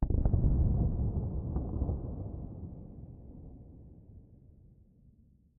basaltground2.ogg